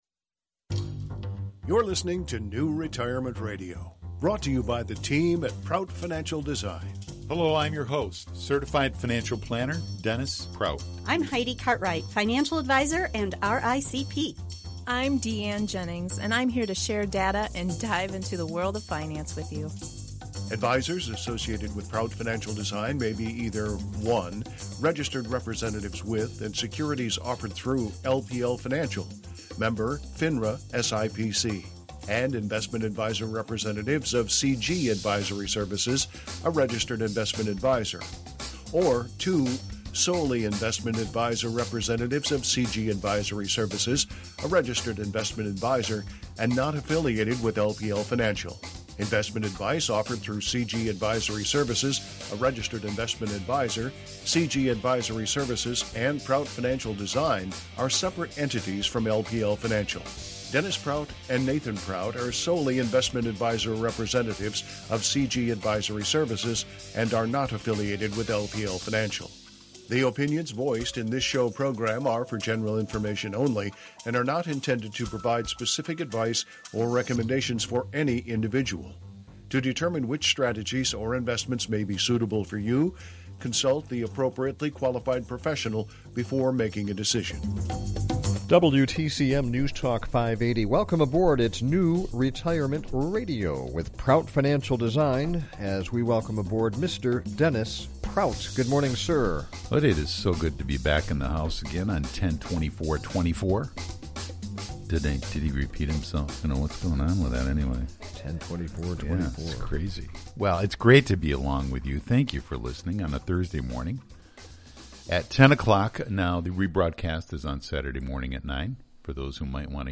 will join us LIVE on the show today. They will cover all the basics of Medicare as well as talk about the open enrollment period, known as the Medicare Advantage and Prescription Drug Plan annual election period, or AEP, which is October 15 through December 7.